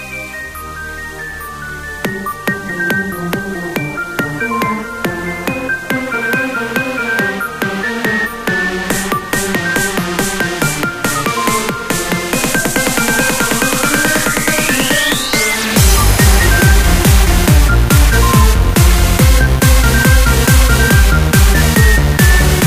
euro-trance style